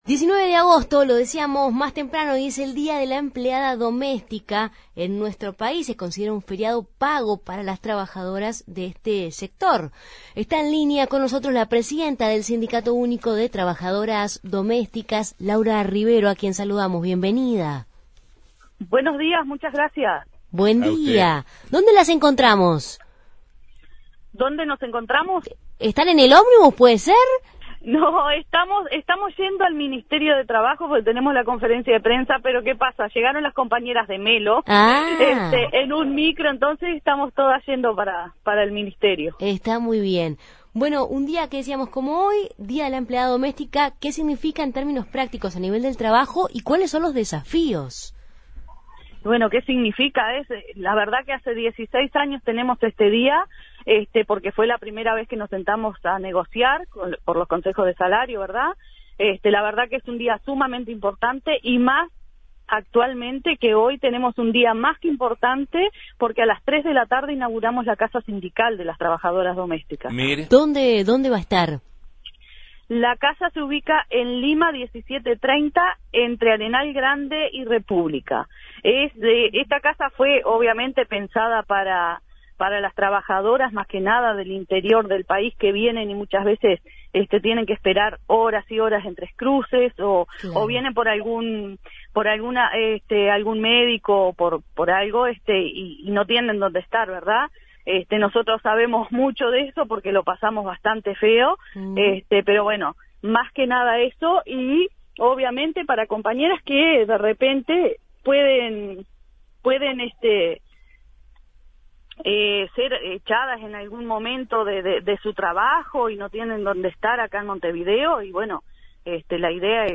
en diálogo con Justos y pecadores.